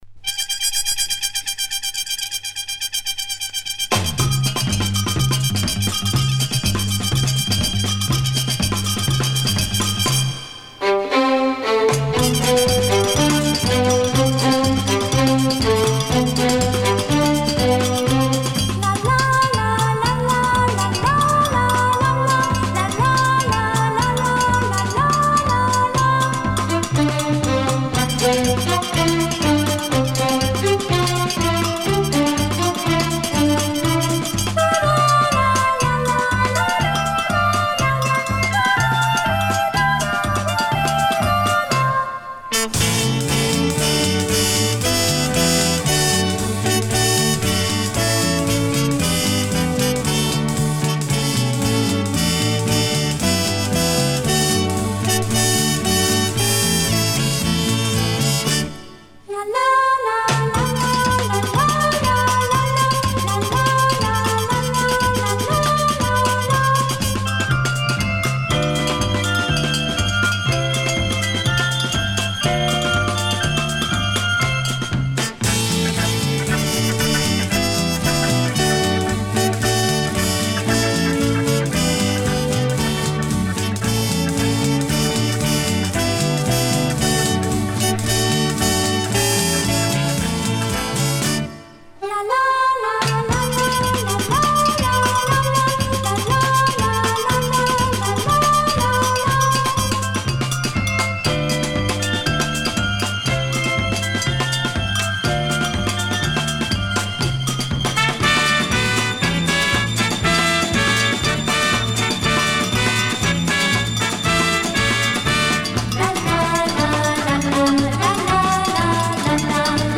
Всюду звучат, затаившие дыхание, женские вокалы.
Genre: Instrumental Pop, Back Vocal,
Easy Listening